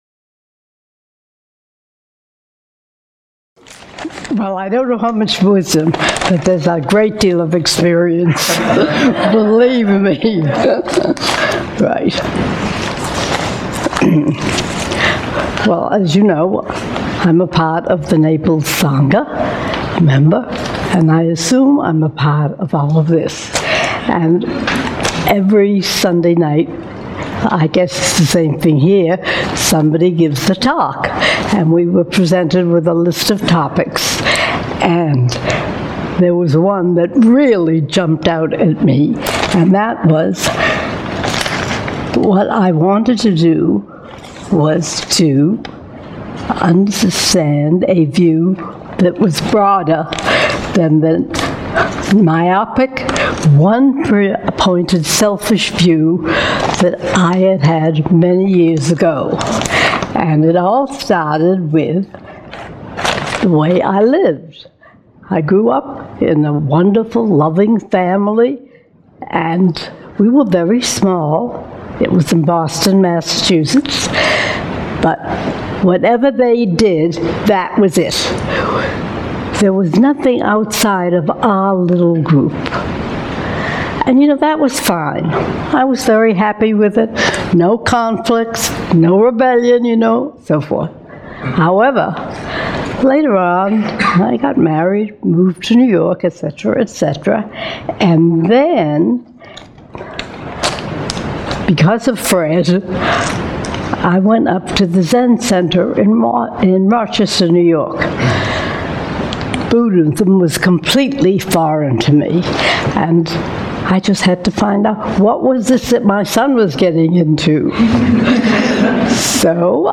Sangha Talk